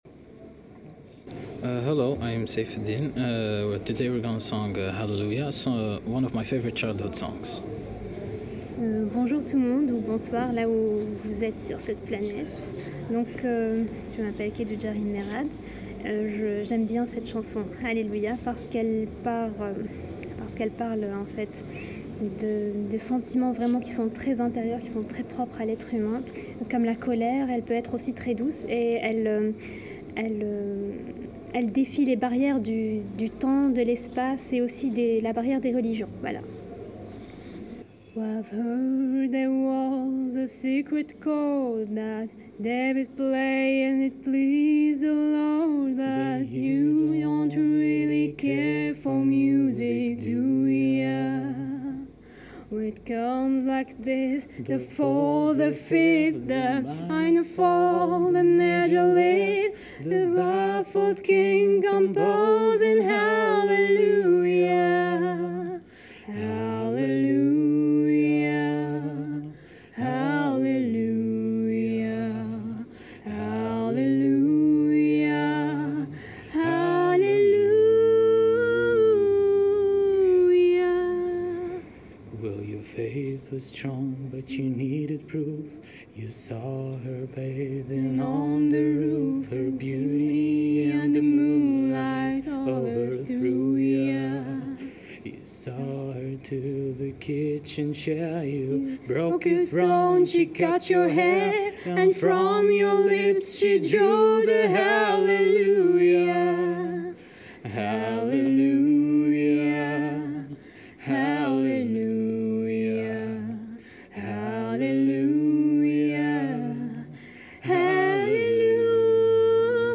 chant en anglais